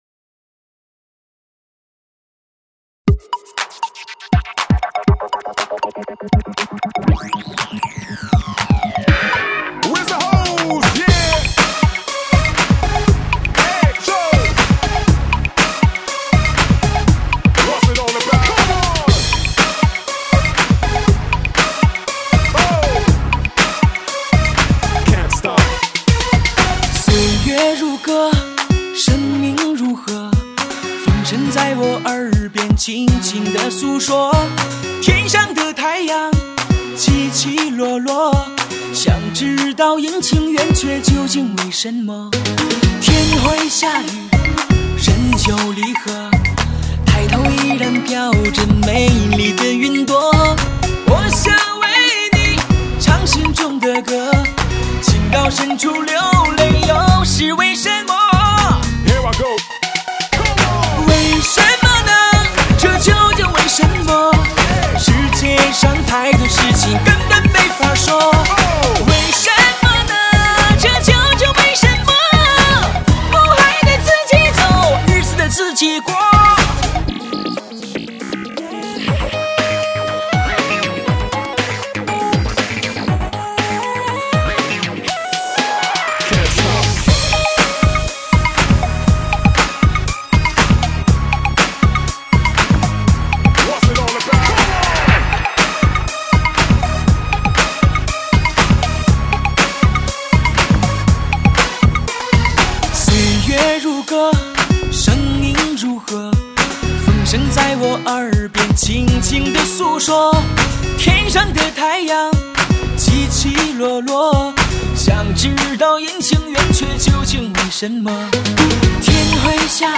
流行情歌，原创流行音乐汇辑，绝对时尚前线